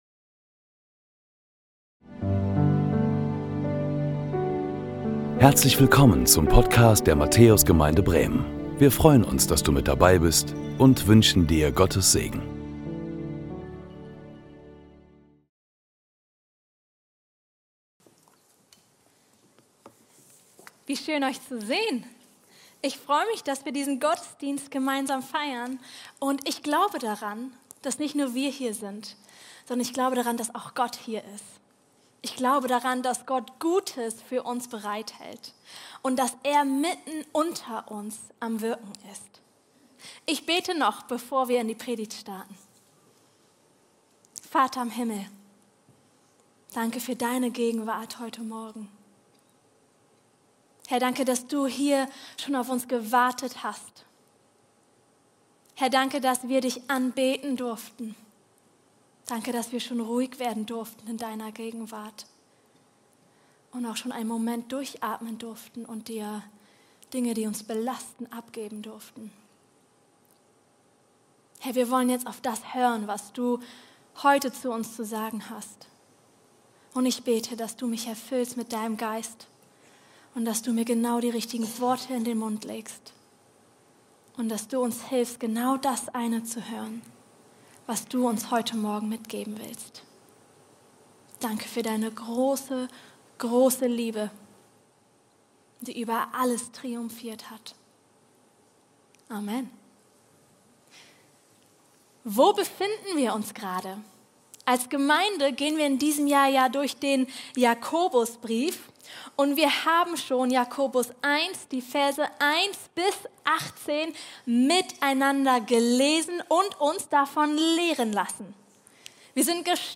Predigten der Matthäus Gemeinde Bremen